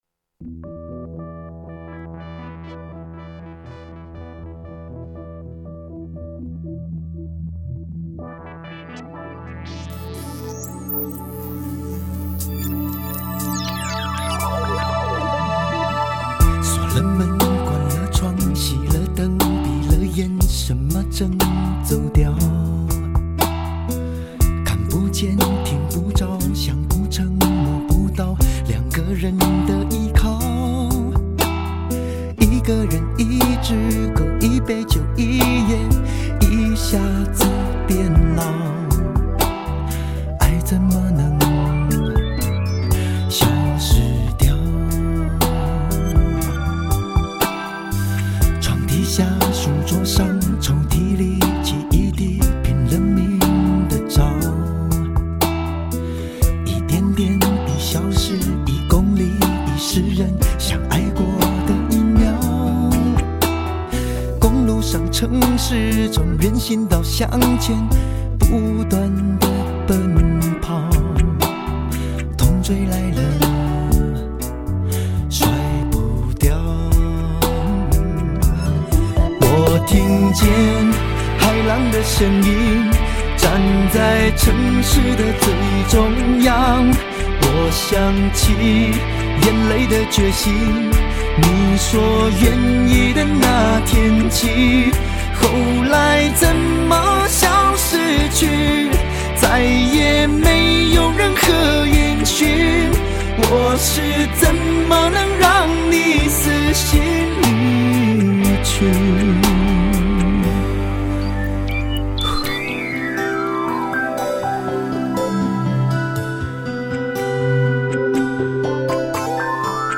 国语流行金曲典范
高清解析 完美音质